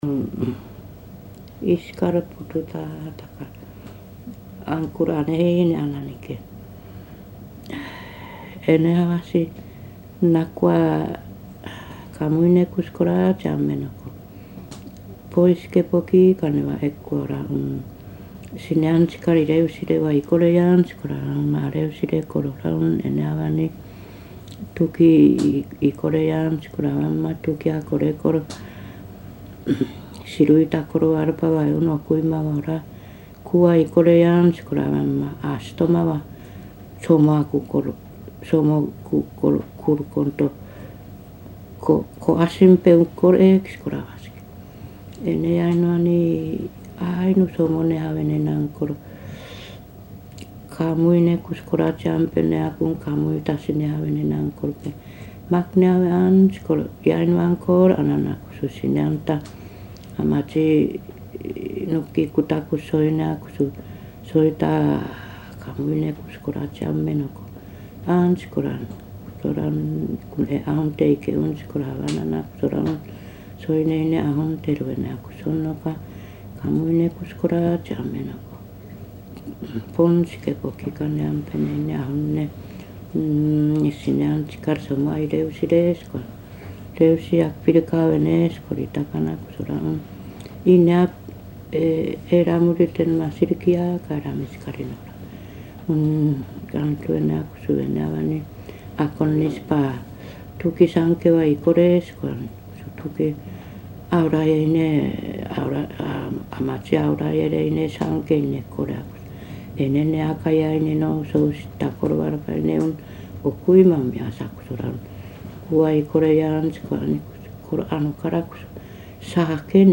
[14-8 散文説話 prose tales] アイヌ語音声 8:17